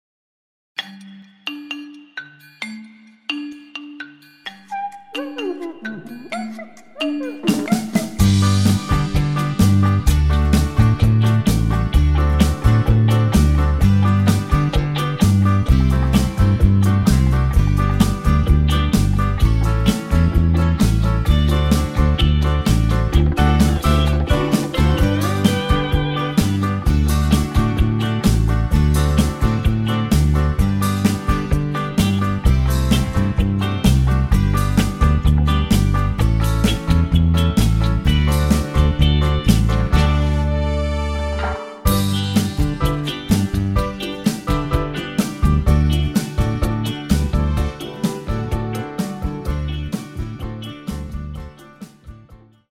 (avec bruitages)